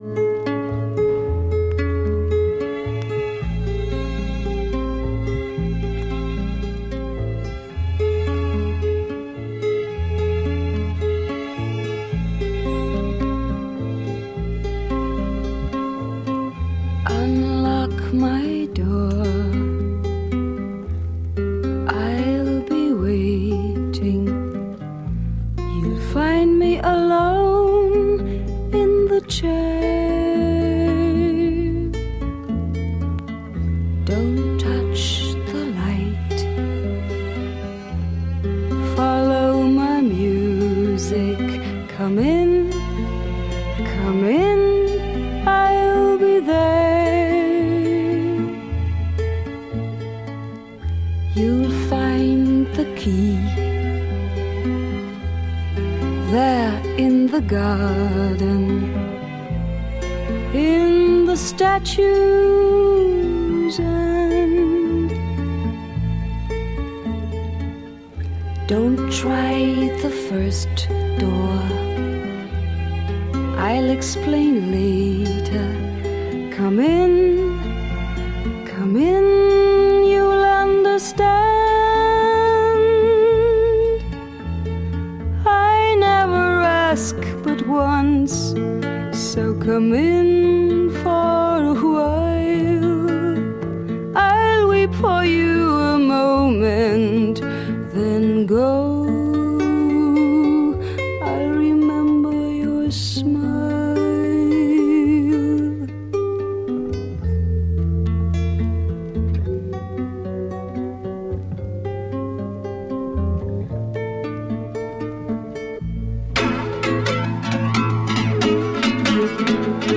Dutch Female Psych